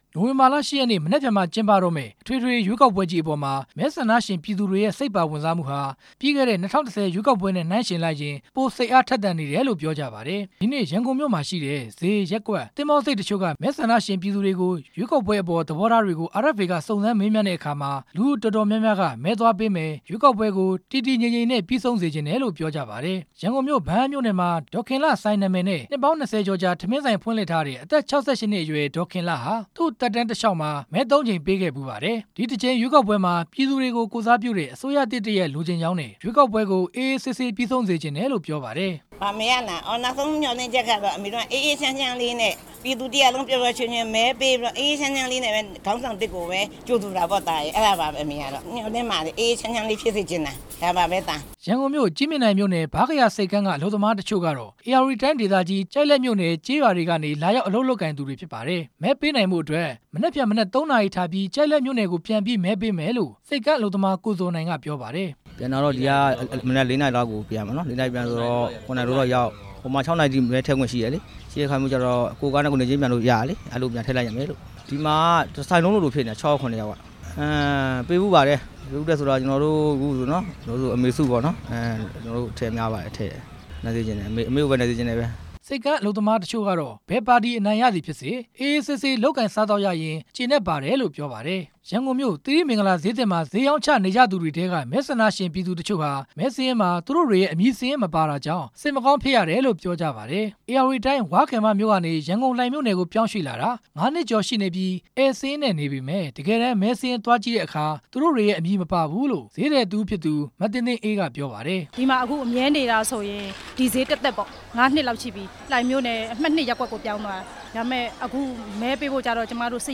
၂ဝ၁၅ ခုနှစ် အထွေထွေ ရွေးကောက်ပွဲကြီးဟာ မနက်ဖြန် နိုဝင်ဘာလ ၈ ရက်နေ့မှာ မြန်မာနိုင်ငံတဝှမ်း ကျင်းပတော့မှာ ဖြစ်ပါတယ်။ RFA သတင်းထောက်တွေဟာ ရန်ကုန်မြို့မှာရှိတဲ့ ဈေး၊ ရပ်ကွက်နဲ့ သဘောၤဆိပ်စတဲ့ နေရာတွေက မဲဆန္ဒရှင်ပြည်သူတွေကို ရွေးကောက်ပွဲအပေါ်မှာ စိတ်ပါဝင်စားမှု အခြေအနေကို လိုက်လံမေးမြန်းတဲ့အခါမှာ တော်တော်များများက မဲသွားပေးမယ်ဆိုတာ စိတ်အားထက်ထက်သန်သန် ပြောကြပါတယ်။